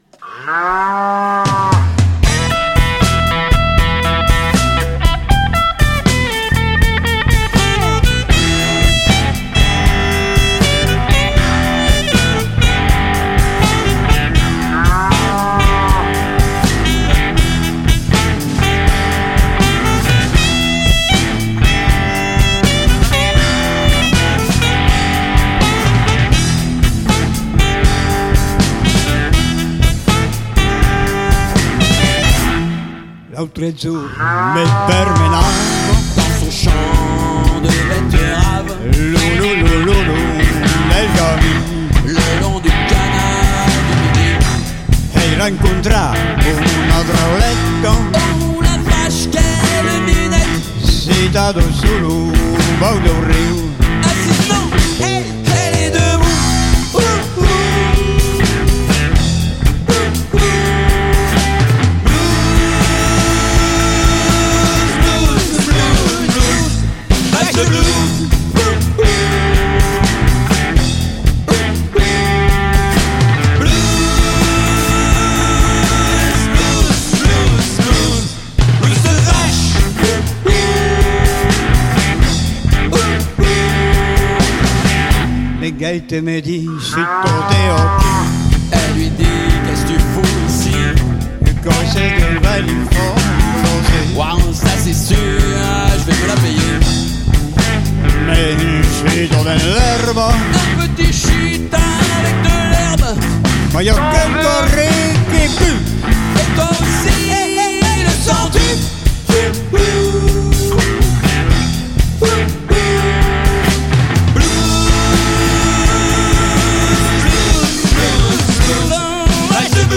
une version live
ambiance de feu